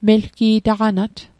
Pronunciation Guide: mel·kii·da·hga·nat Translation: He/she has a strong neck